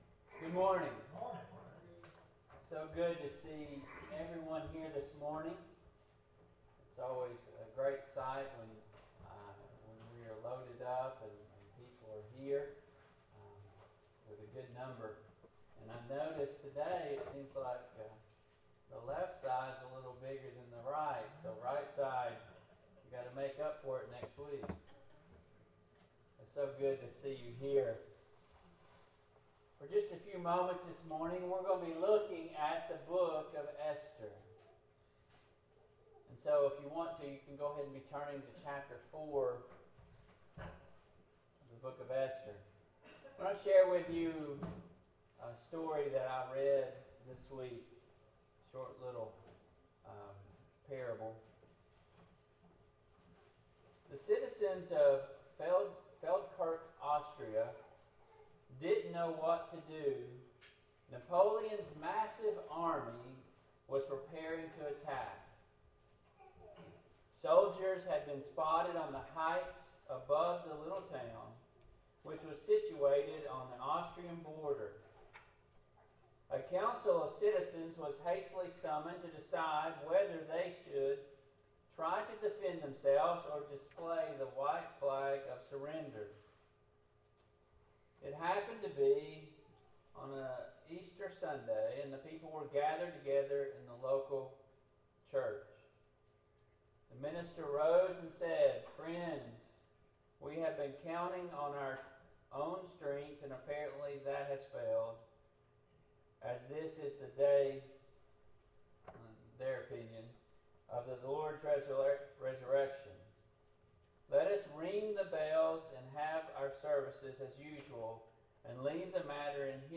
Service Type: AM Worship